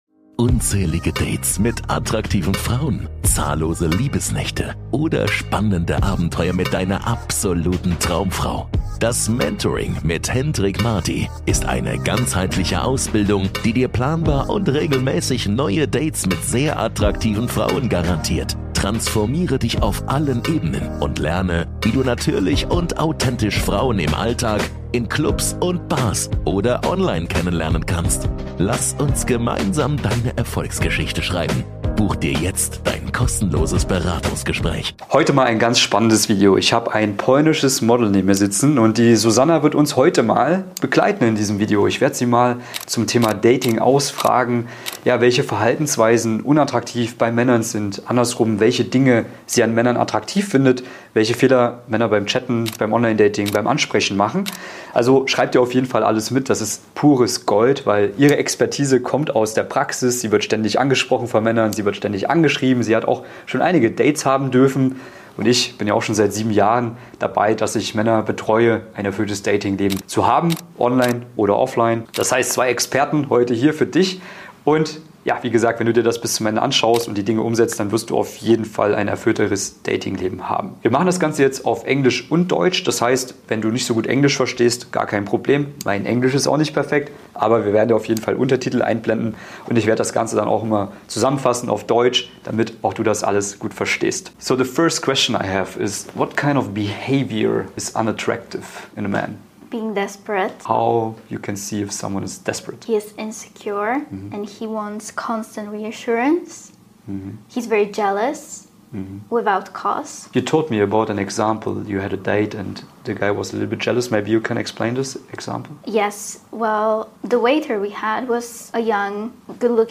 Ich analysiere ihre Aussagen als erfahrener deutscher Dating Coach und zerlege die größten Fehler, die Männer beim Flirten, beim Dating und auf dem Weg zur Beziehung machen.